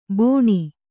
♪ bōṇi